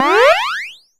retro_jump_collect_bonus_02.wav